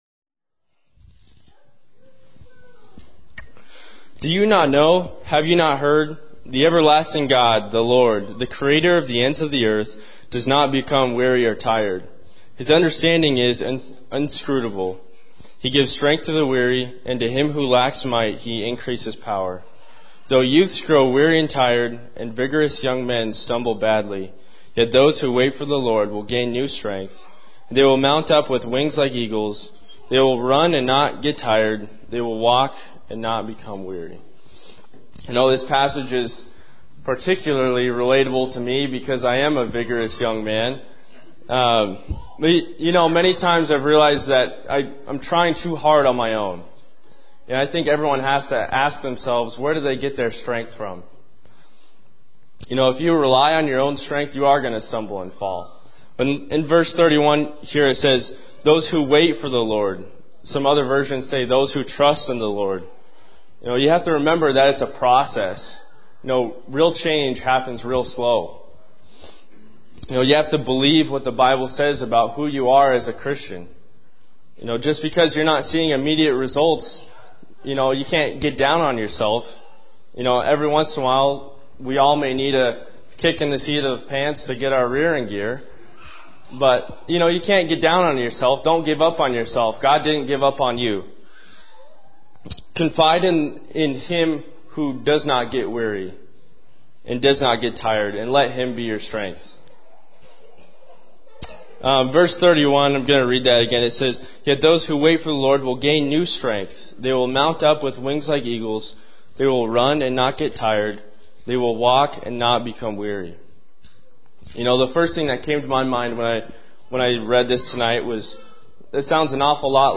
We finish out 2017 with an evening of Shotgun Preaching!